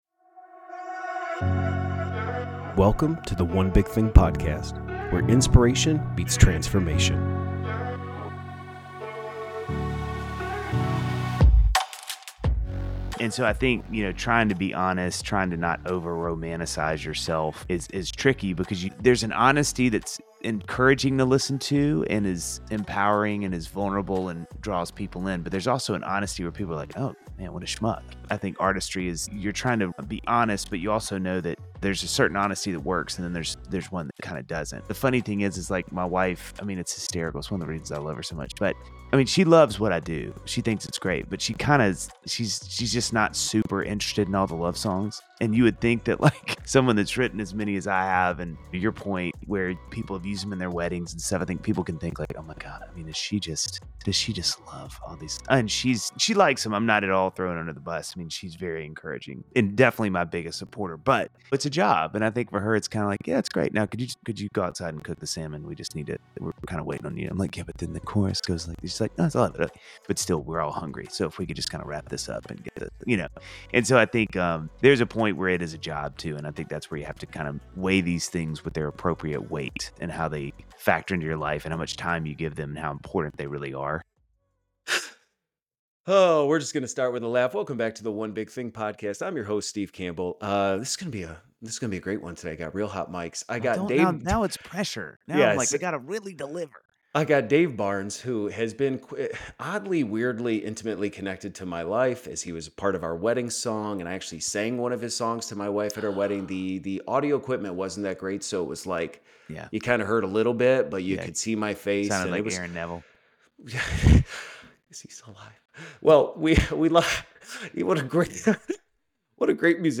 We couldn't stop laughing....get ready for a delightful and candid conversation with the incredibly talented and funny singer-songwriter Dave Barnes, who opens up about the challenges and joys of balancing artistry, fatherhood, and the expectations that come with both.